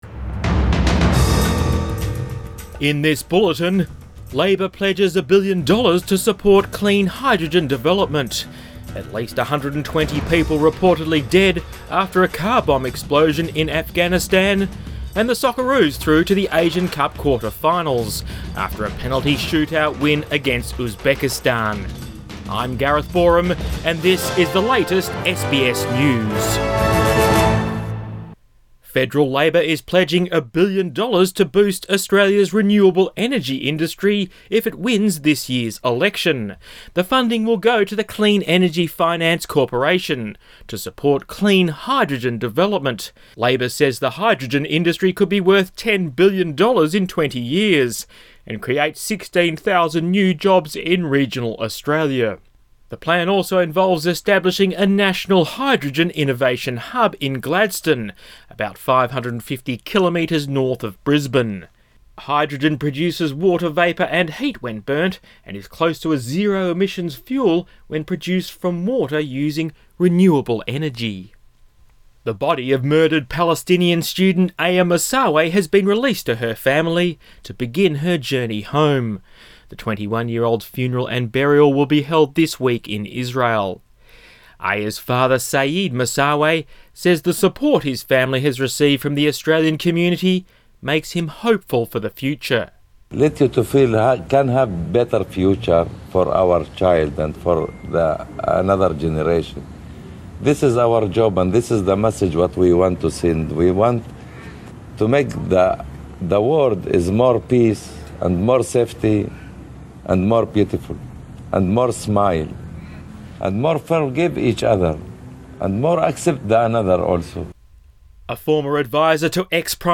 Midday Bulletin 22 January